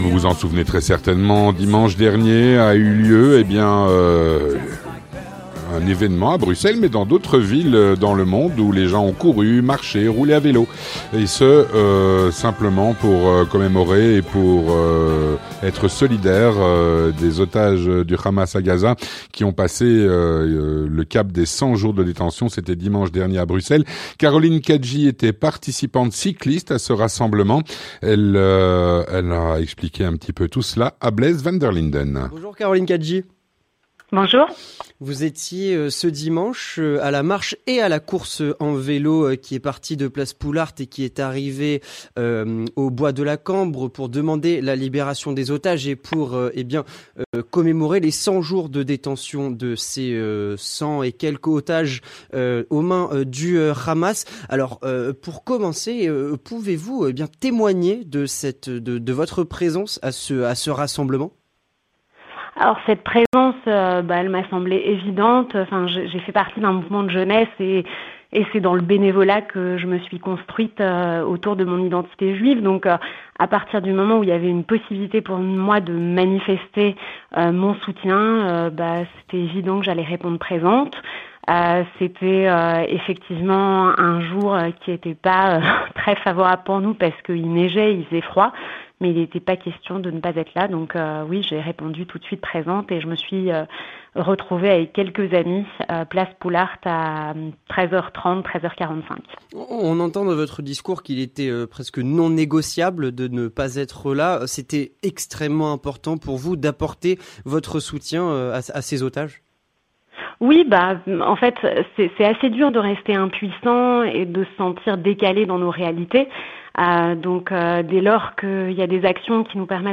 Une participante cycliste au rassemblement pour les 100 jours de détention des otages, dimanche  dernier à Bruxelles, témoigne.